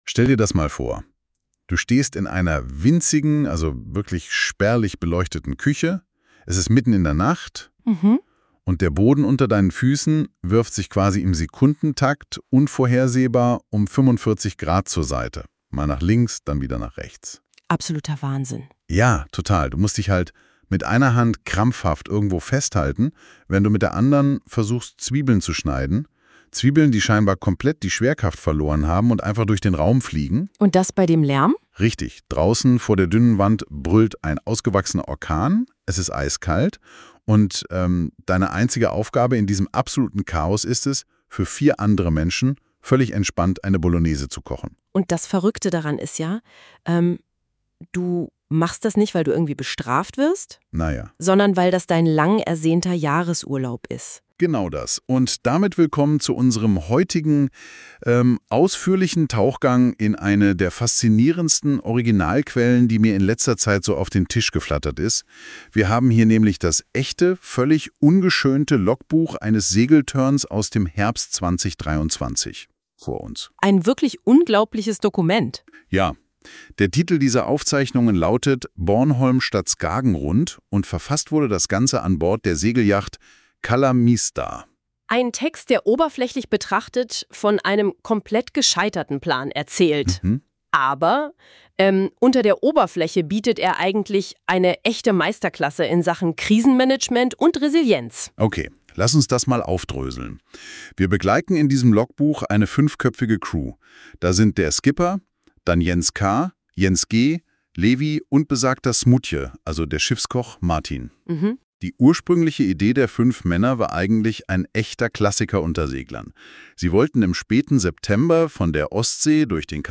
Das Seewetter hat uns nicht "Skagen Rund" gelassen, dafür nach Bornholm. Von dieser Reise erzählt das Logbuch und ein von NotebookLM erzeugter Podcast